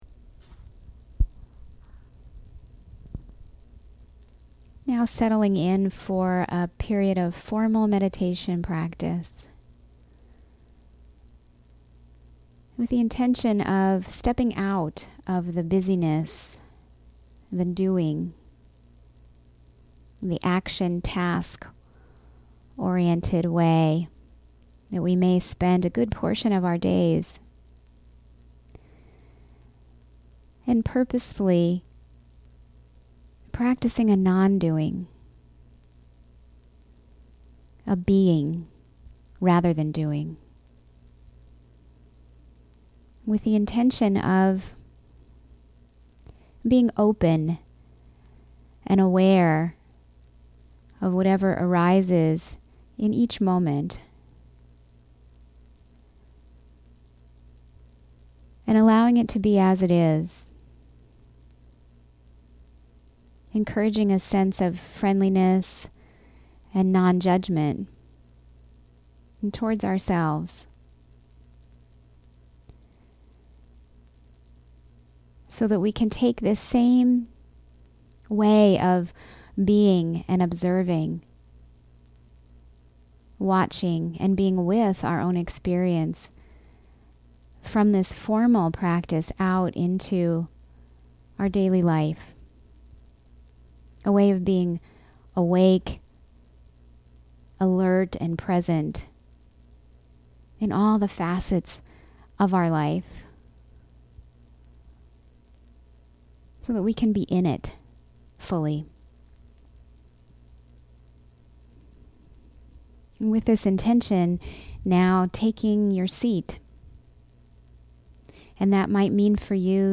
Open Awareness Meditation - 42 minutes